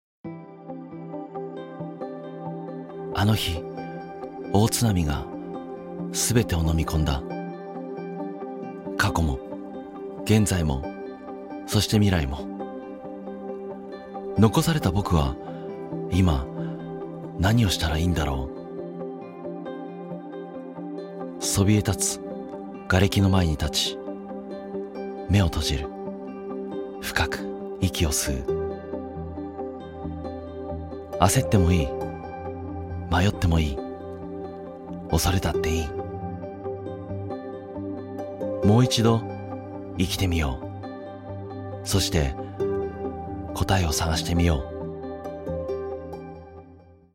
una voz de barítono enérgica y confiable
Muestras de voz nativa
Trailers de películas